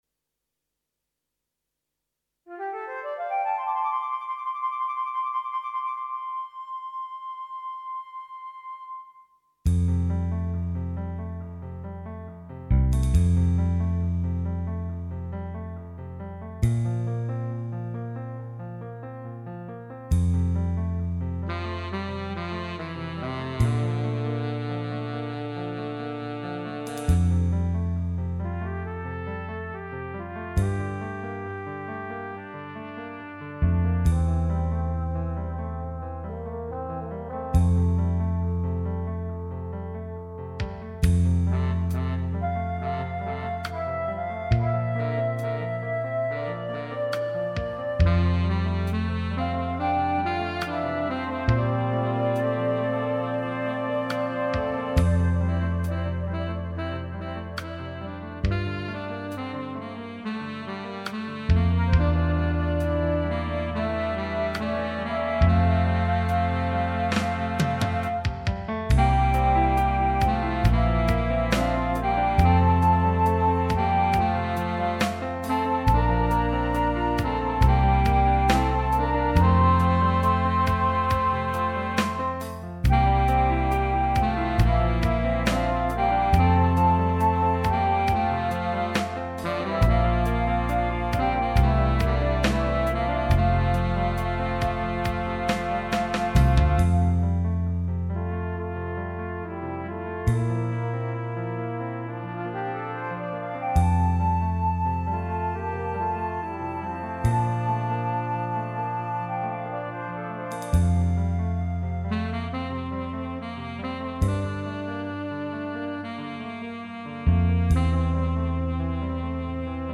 minus Instrument 2